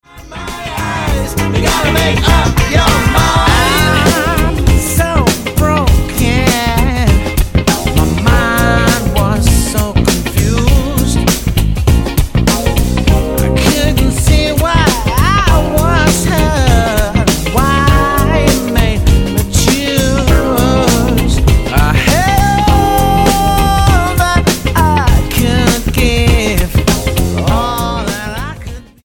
Greek Cypriot soul singer
Style: Pop